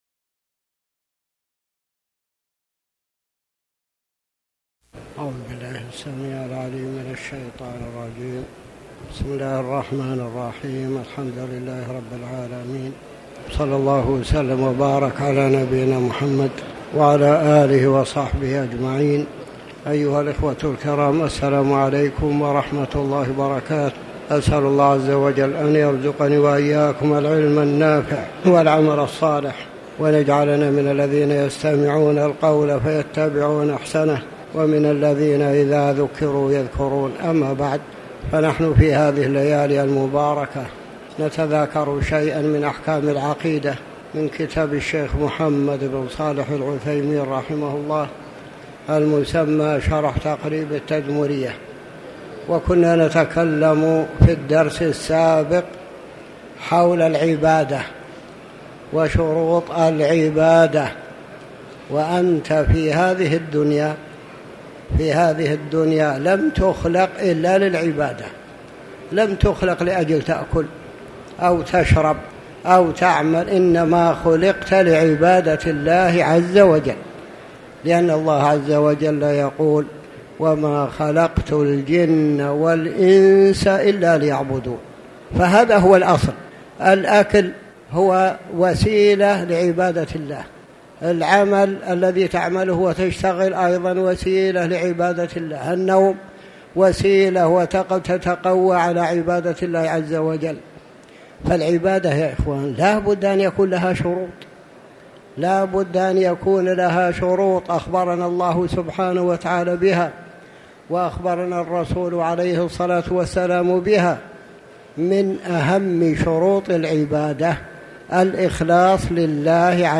تاريخ النشر ٢٩ ربيع الثاني ١٤٤٠ هـ المكان: المسجد الحرام الشيخ